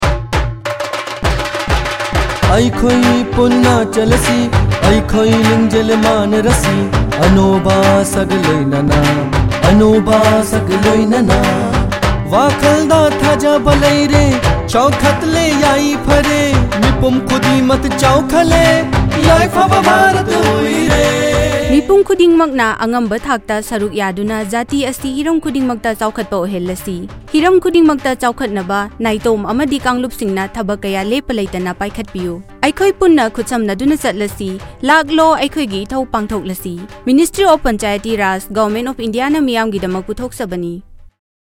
144 Fundamental Duty 10th Fundamental Duty Strive for excellence Radio Jingle Manipuri